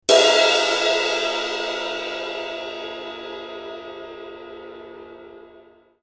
ZILDJIAN ( ジルジャン ) >A ZILDJIAN ROCK CRASH 18
ZILDJIAN（ジルジャン）の18インチロッククラッシュ。明るく切れの良い存在感あるサウンドを持つモデル。